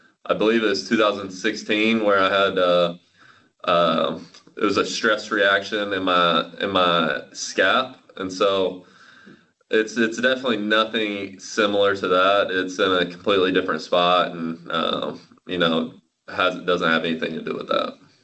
Please listen to Zoom audio on Wacha below.
Zoom audio on Mike Wacha